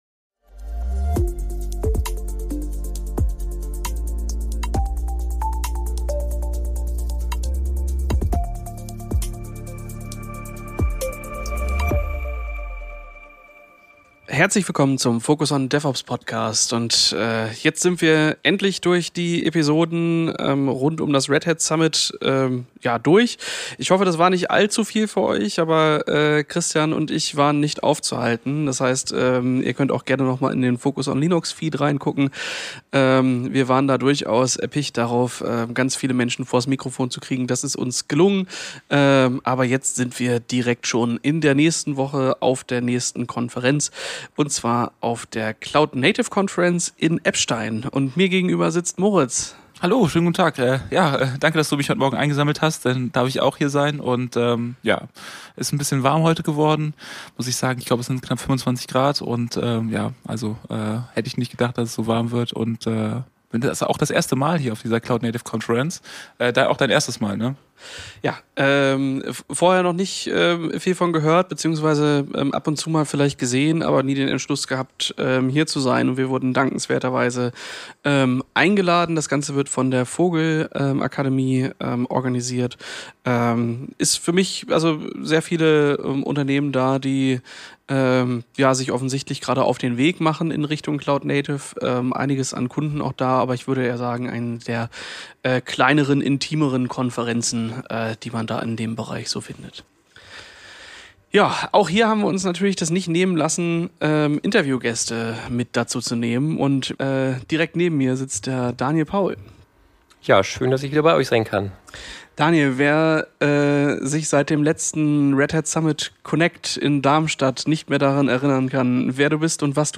In dieser Ausgabe des Focus On DevOps Podcasts bringen wir frische Eindrücke von der Cloud Native Conference in Eppstein.